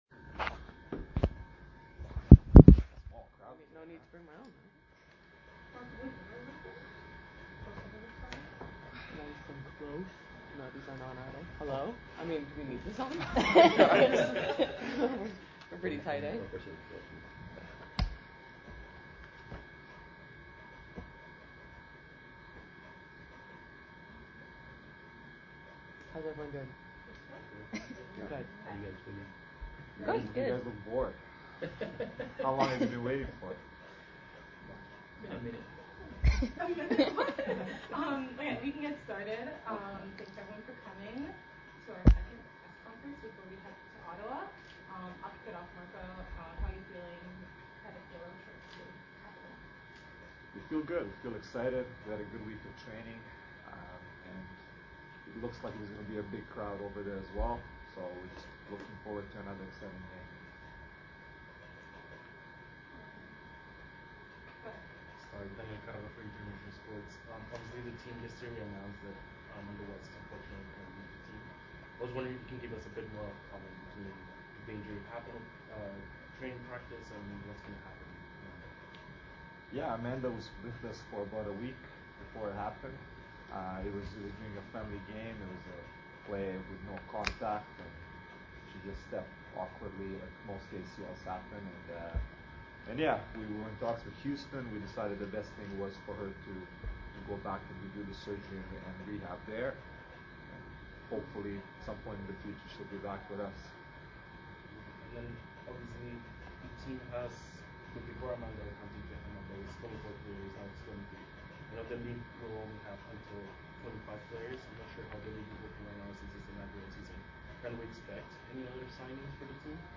April 25, 2025...press conference of AFC Toronto before their trip to Ottawa for Sunday's game